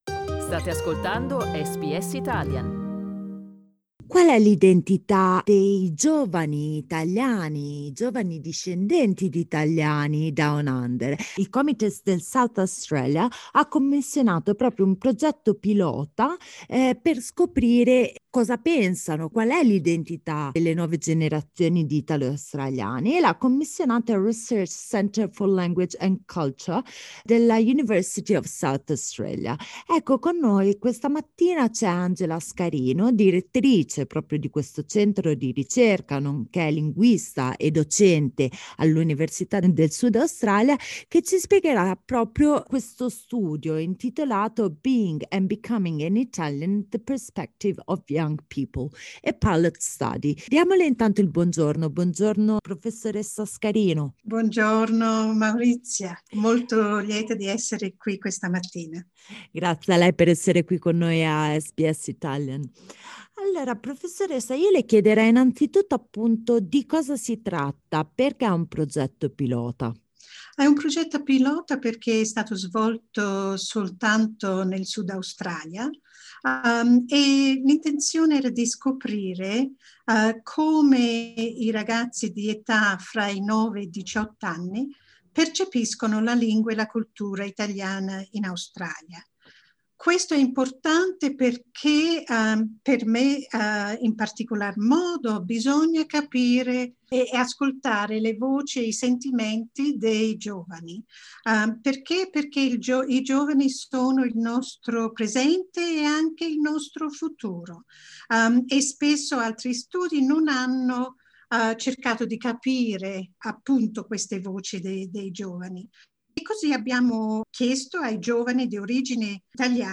Source: Research Centre for Language and Culture Ascolta la conversazione sull’italiano e i suoi dialetti, ma anche sulle storie di migrazione italiane: LISTEN TO Qual è l’identità dei giovani italo-australiani?